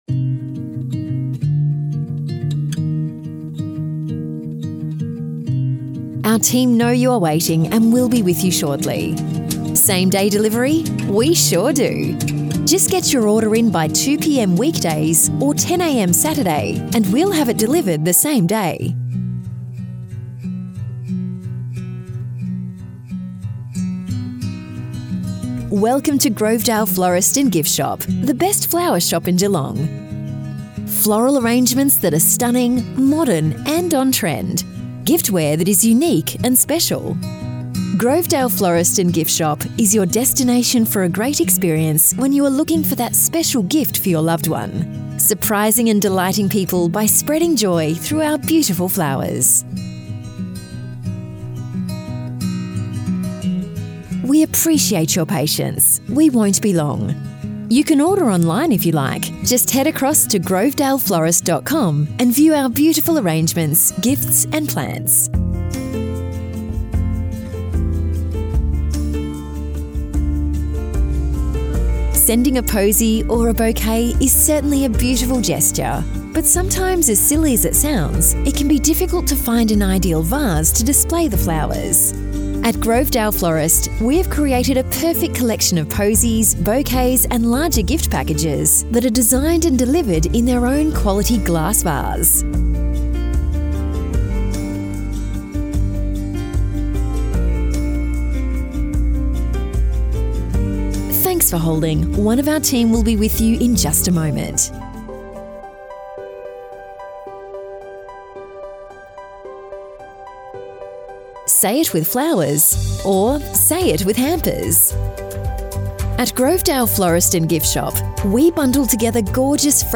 grovedale-florist.mp3